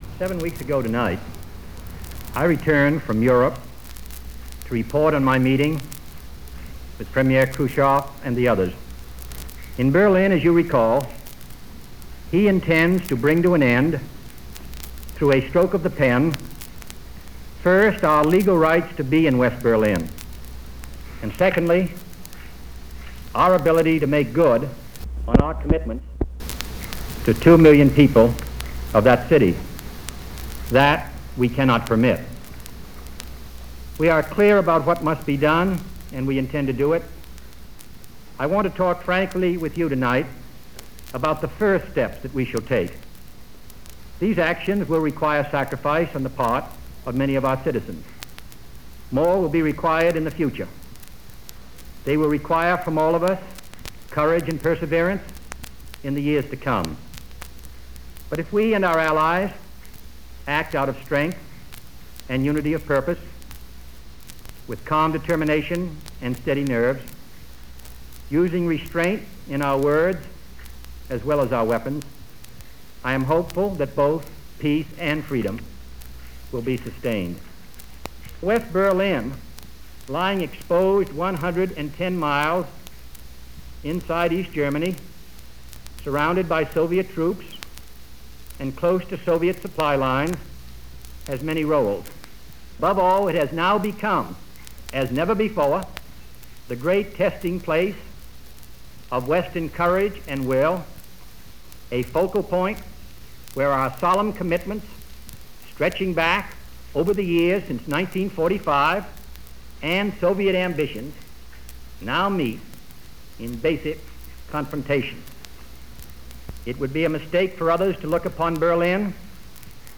U.S. President John F. Kennedy's speech on the Berlin crisis